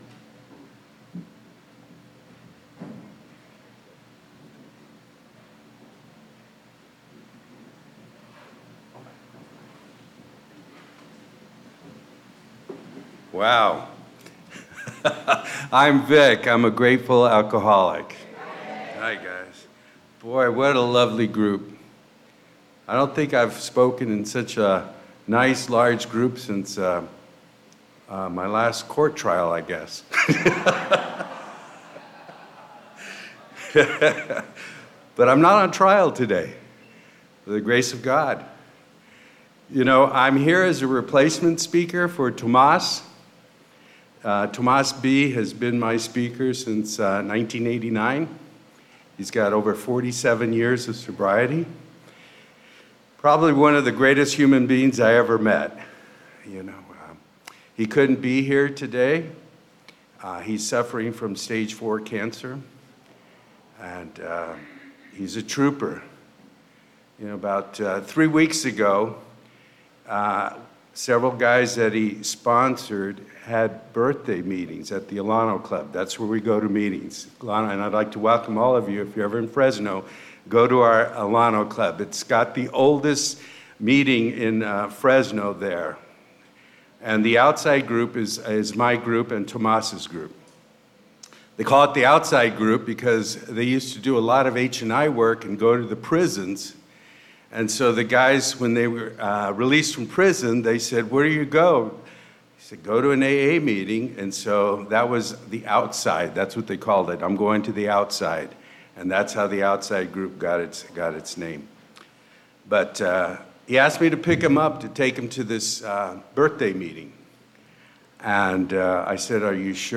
Saturday Morning AA Speaker &#8211